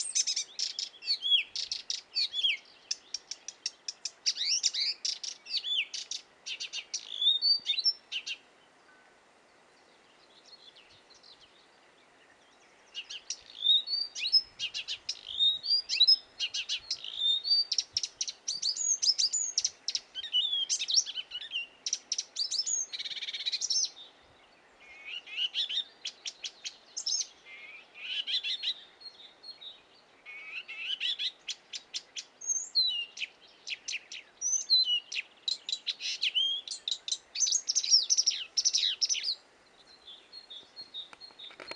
Соловей.
тема:   животные
песня прилагается.